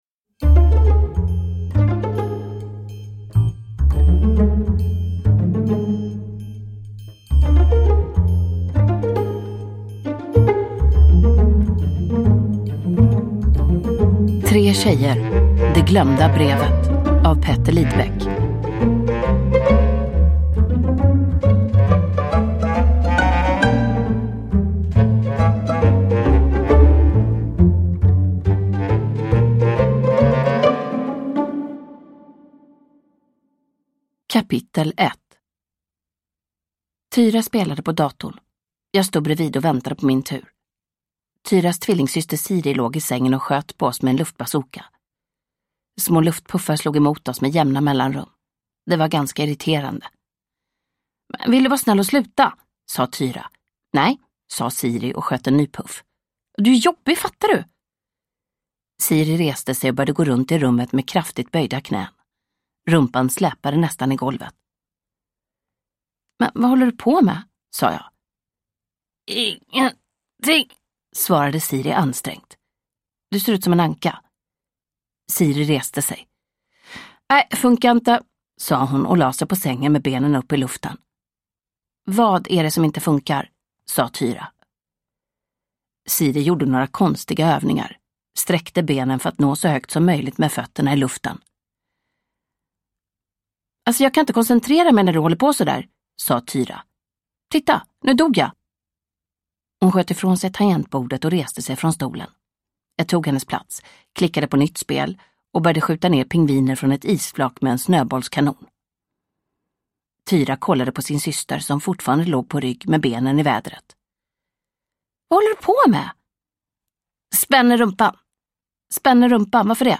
Det glömda brevet – Ljudbok – Laddas ner